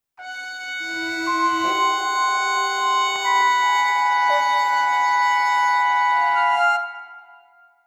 Here’s the solution (capture.aiff) I get with recording the output to an extern recorder.